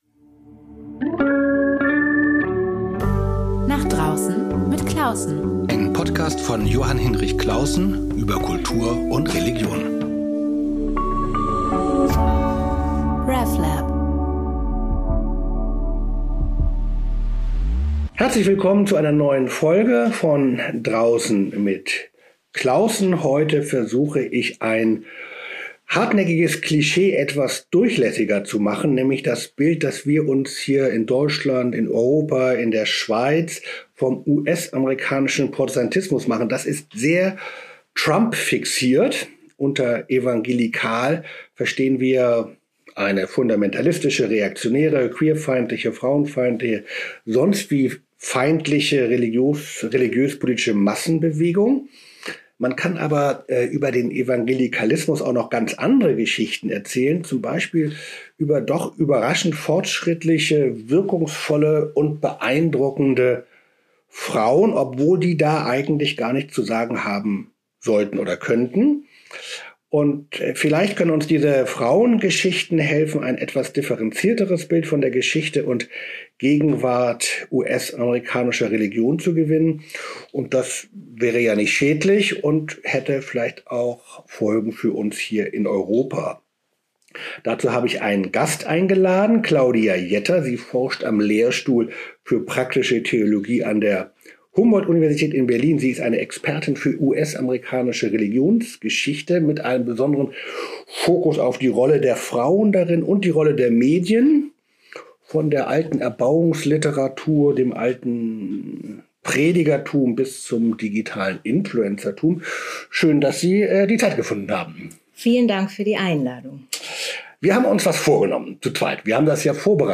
Über überraschende kulturelle Entwicklungen, tolle neue Kunstwerke oder aktuelle Konflikte. Nicht als journalistisches Frage-Antwort-Spiel, sondern als gemeinsames, ernsthaft-unterhaltsames Nachdenken.